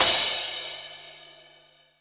43_07_cymbal.wav